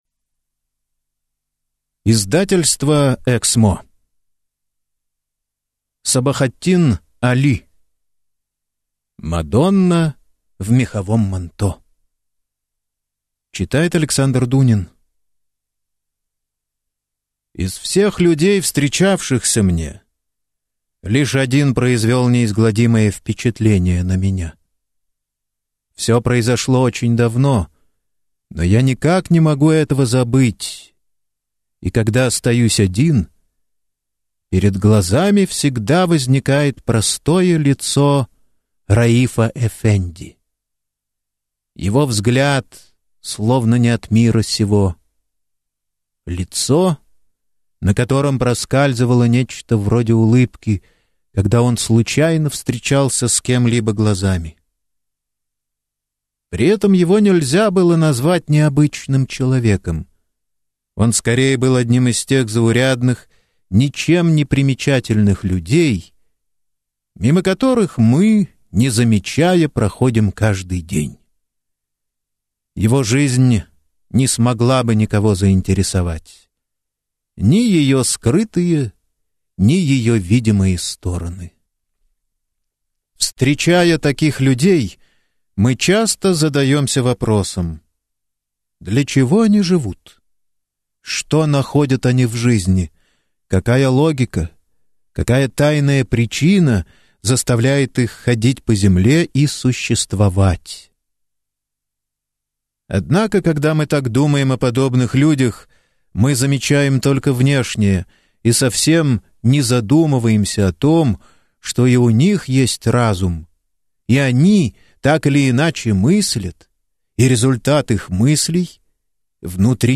Аудиокнига Мадонна в меховом манто | Библиотека аудиокниг